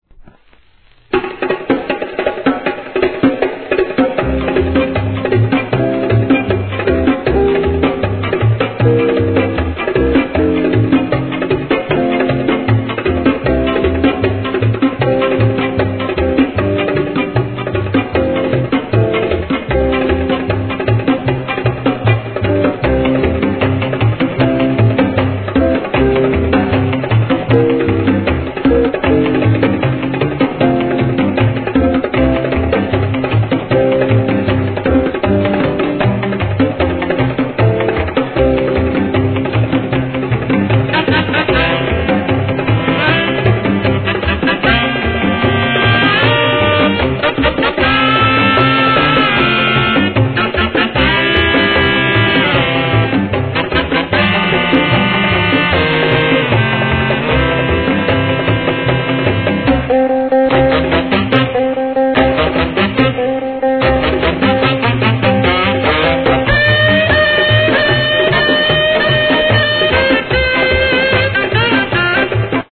SOUL/FUNK/etc...
エスニックJAZZ FUNK!!